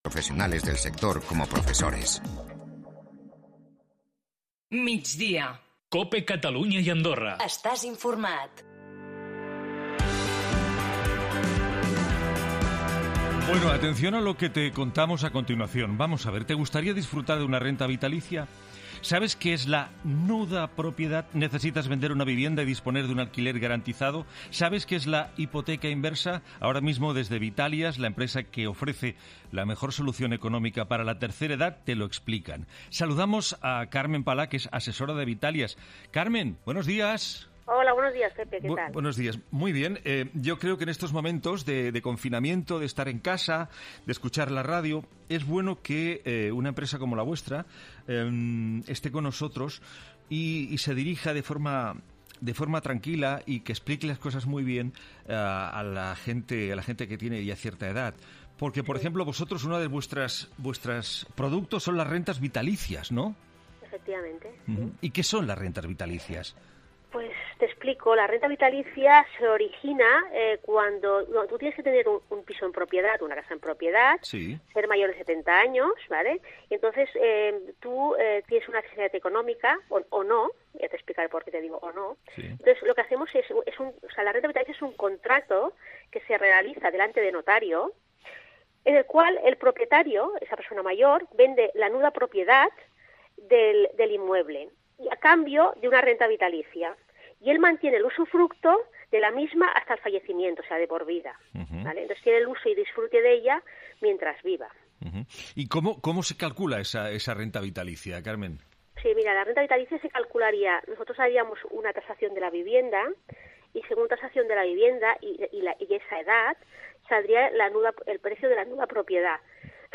Entrevista del 14 de mayo en el programa de Herrera